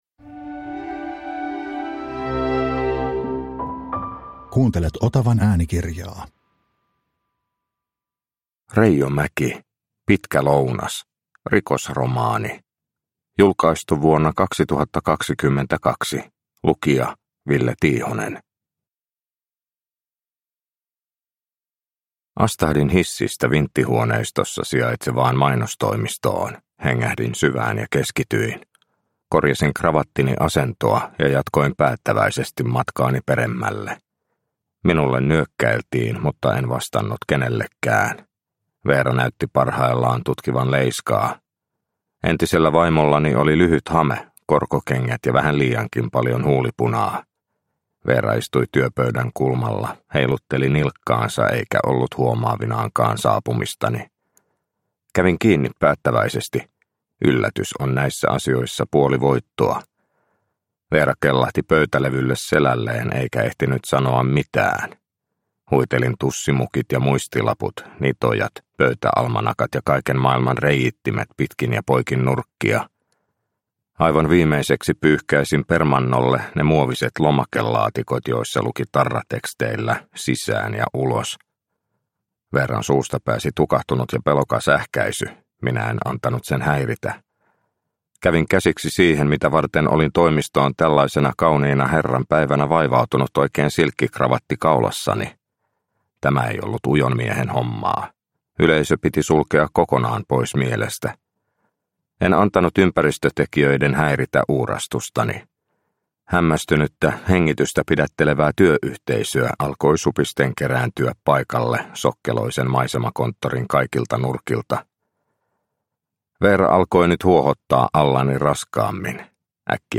Pitkä lounas – Ljudbok – Laddas ner